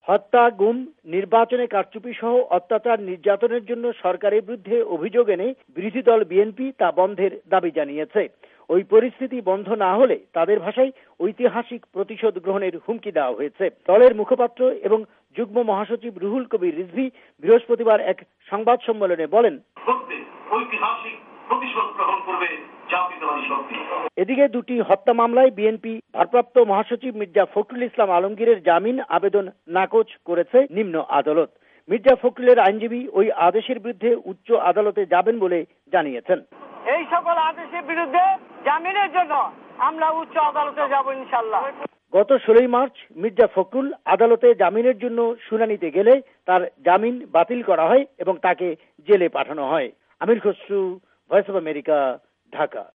প্রতিবেদন: